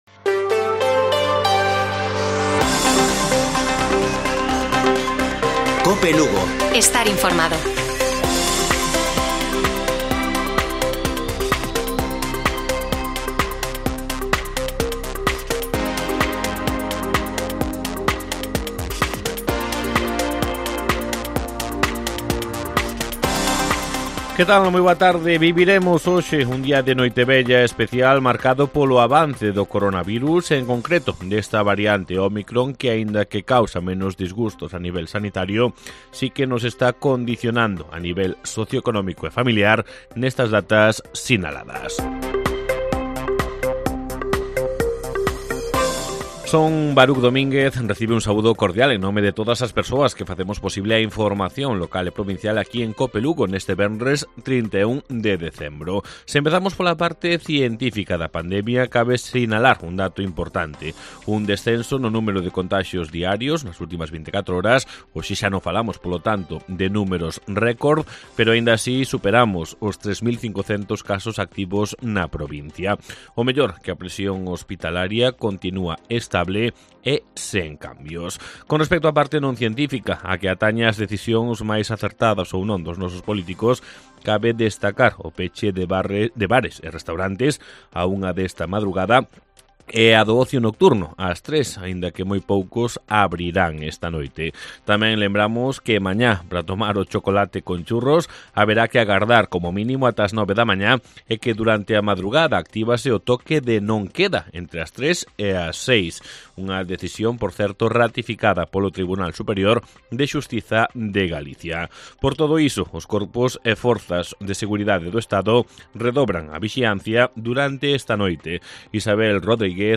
Informativo Mediodía de Cope Lugo. 31 de diciembre. 13:20 horas